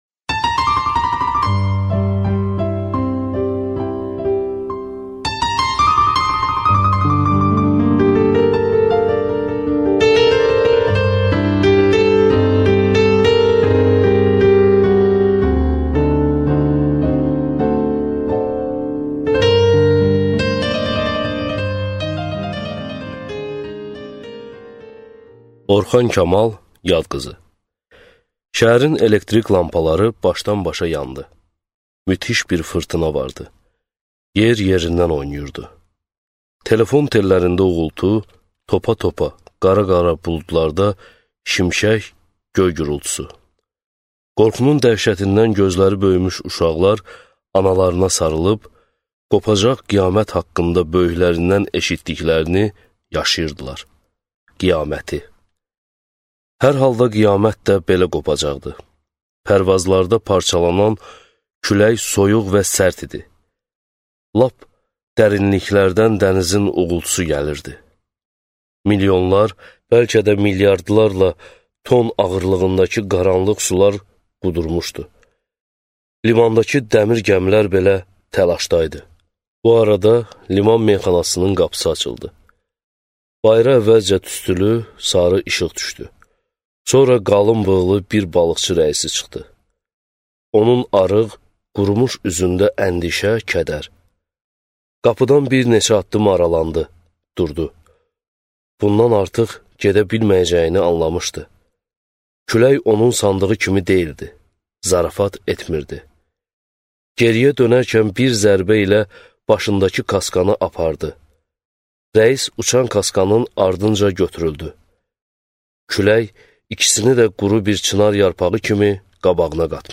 Аудиокнига Yad qızı | Библиотека аудиокниг